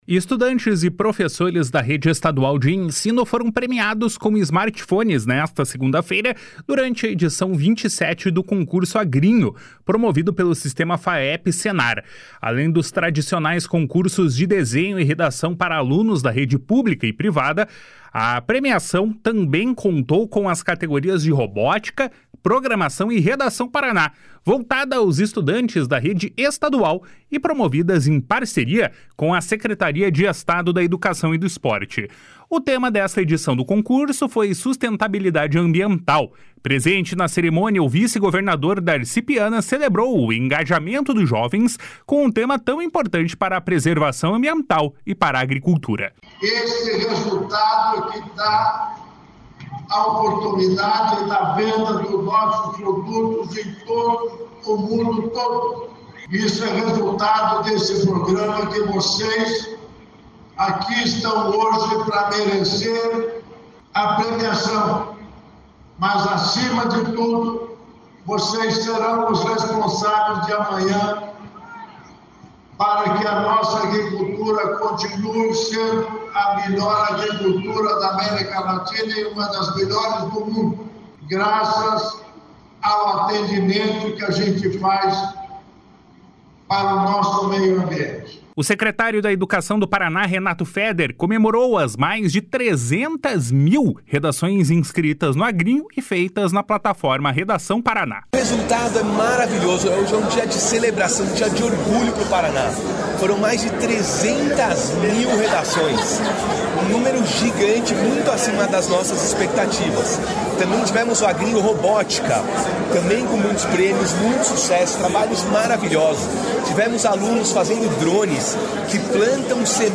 // SONORA DARCI PIANA //
// SONORA RENATO FEDER //
// SONORA NORBERTO ORTIGARA //